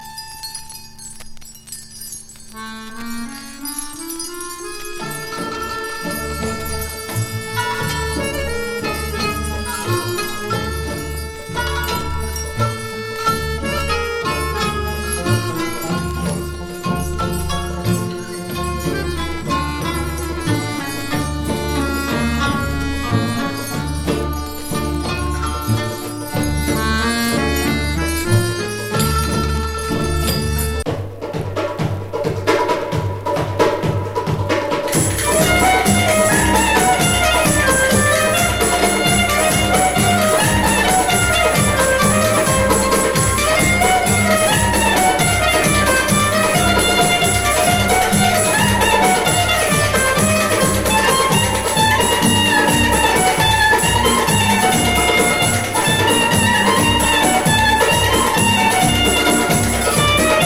ウード、クラリネット奏者の中東～東欧エキゾチックサウンド楽団レコード。